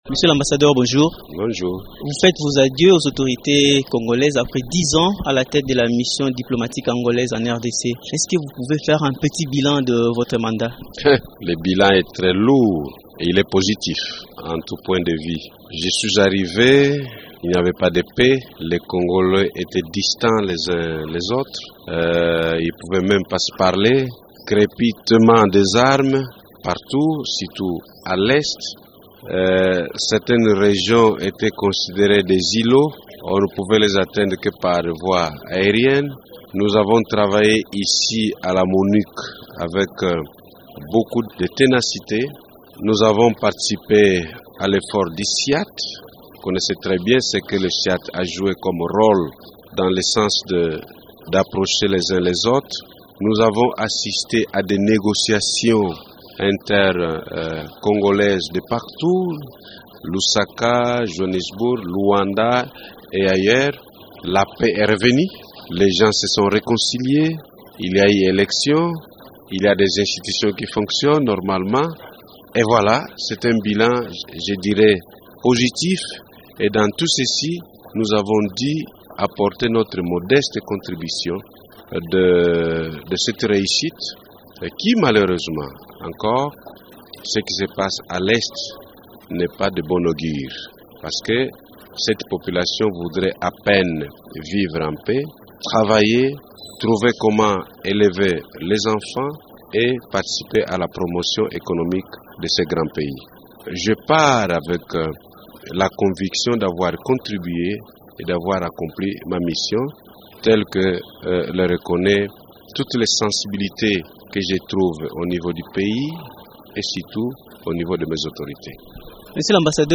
Il quitte la république démocratique du Congo après 10 ans à la tête de la mission diplomatique angolaise en RDC. Quel bilan fait-il de son mandat, Joao Baptista Mawete ambassadeur de l’angola en RDC répond dans cet entretien